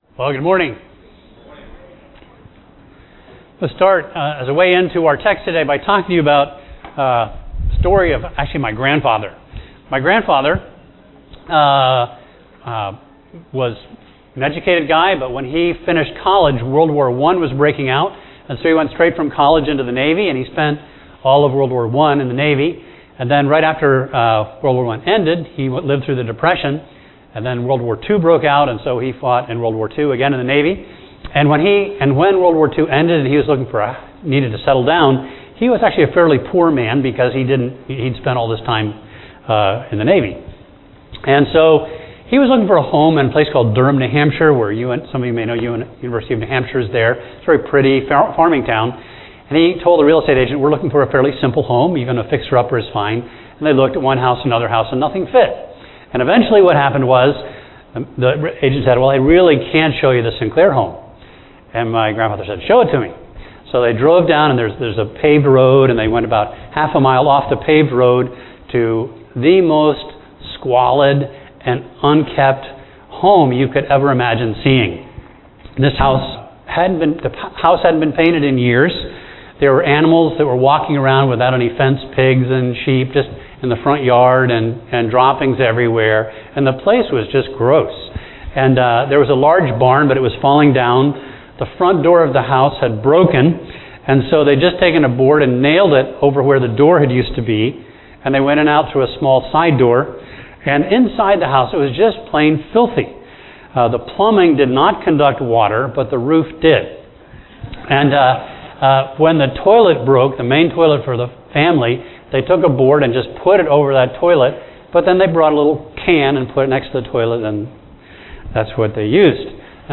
A message from the series "General Topics."